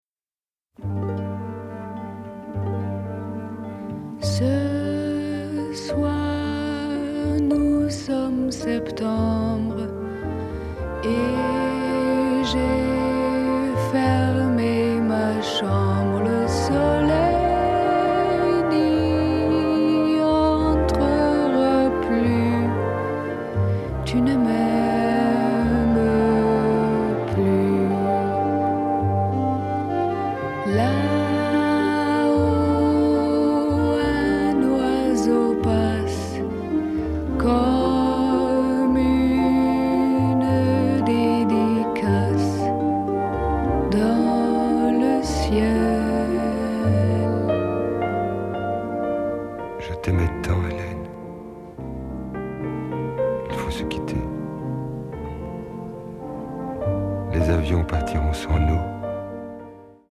Vocal
Romantic and melancholic